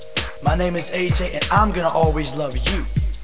AJ talking (35kb)